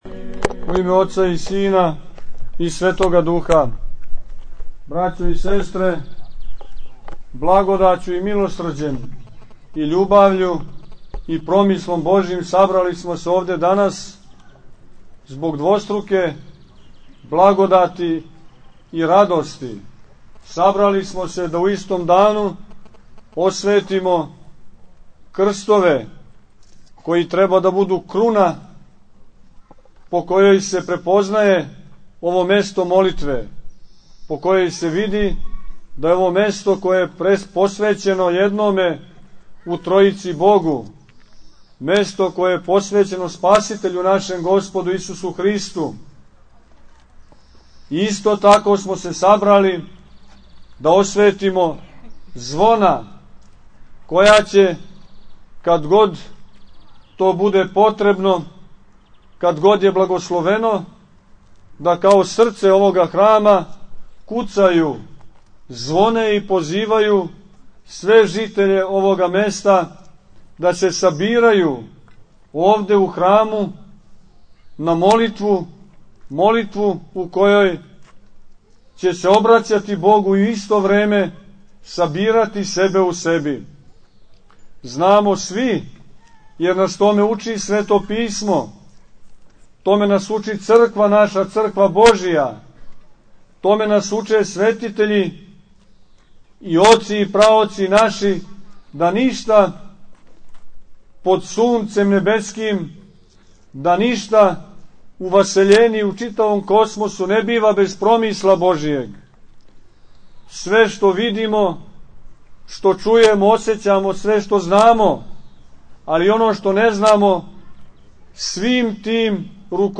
Епископ Порфирије поздравио је све присутне и надахнутим беседама обратио се многобројном верном народу.